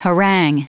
Prononciation du mot harangue en anglais (fichier audio)
harangue.wav